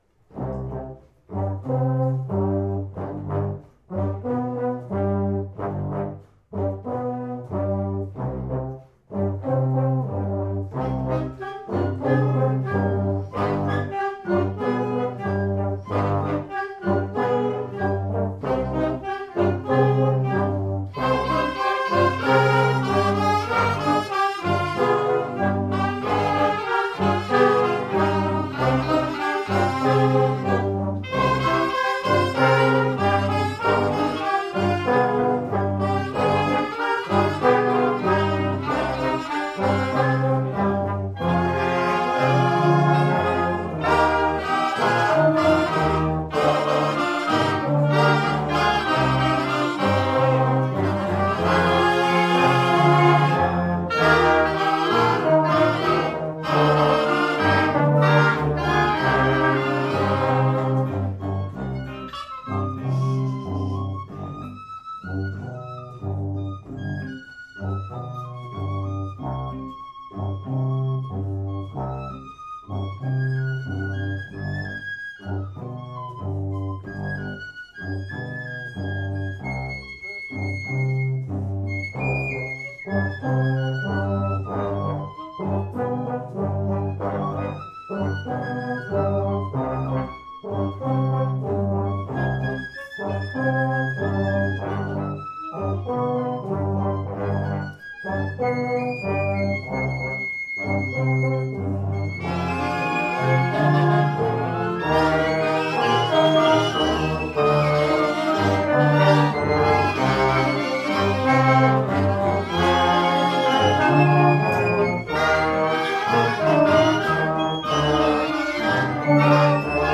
Enregistrements Audio lors du 1er stage 2014